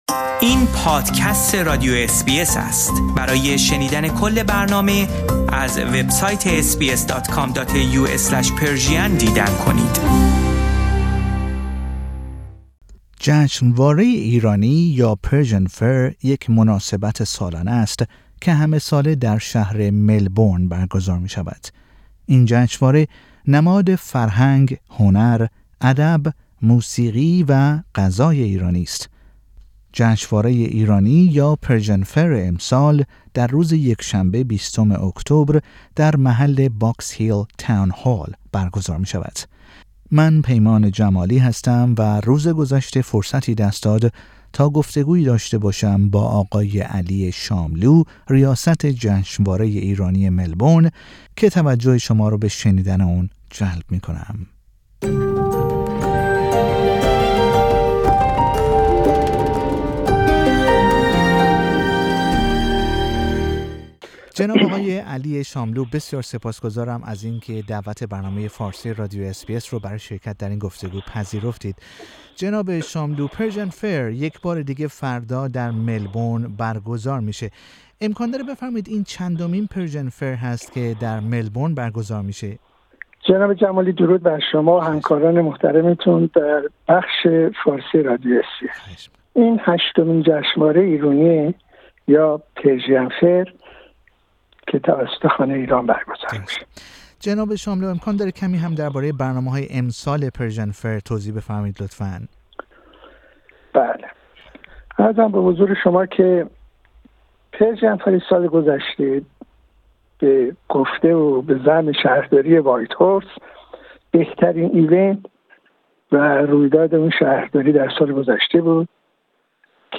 اس بی اس فارسی